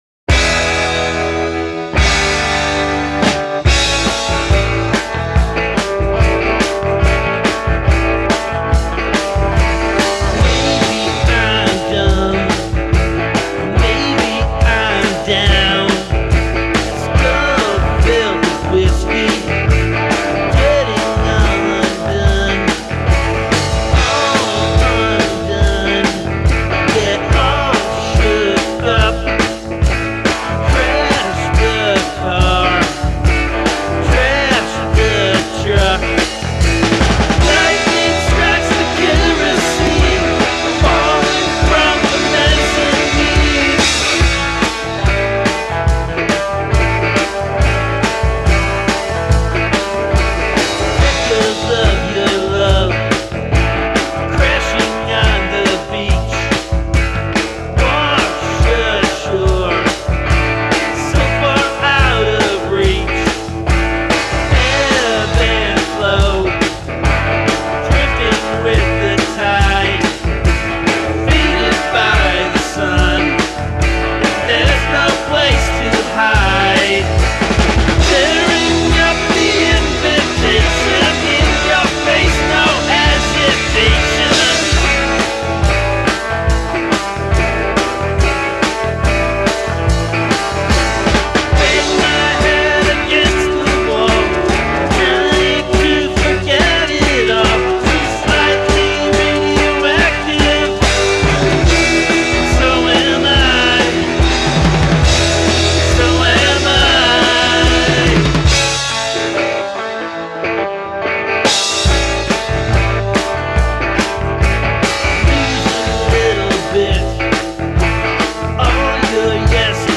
is a great garage single, with super guitar hooks.